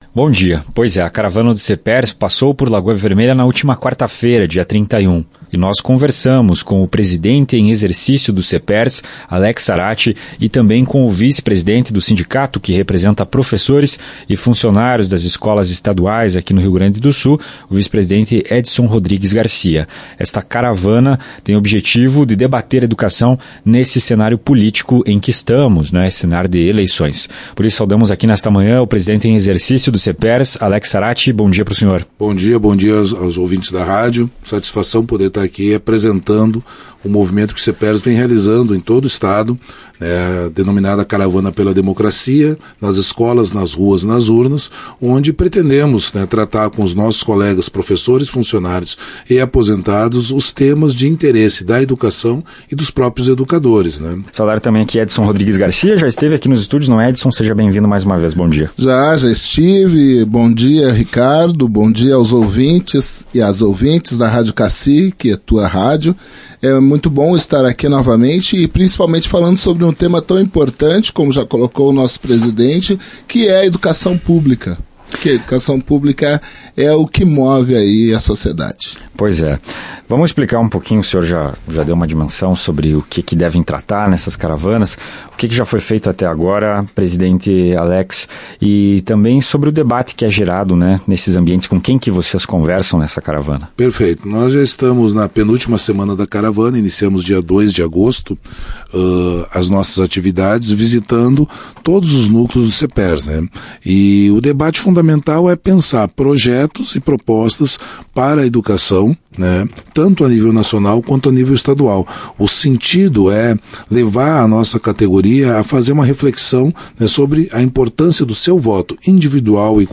Conforme a direção do sindicato, o objetivo é provocar a reflexão sobre temas caros à categoria e fornecer informações que possam dar base a escolha de candidatos com as melhores propostas para o magistério. Ouça a entrevista e saiba mais.